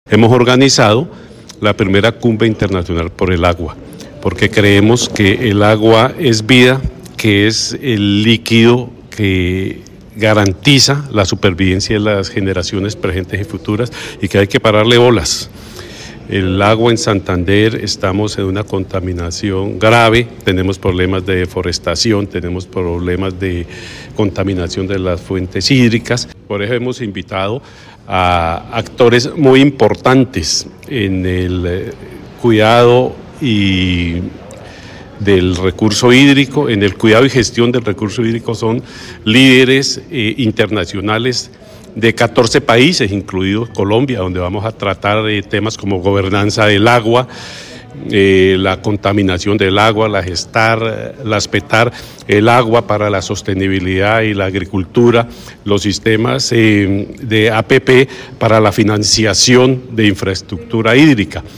Miguel Ángel Castañeda, secretario de ambiente de Santander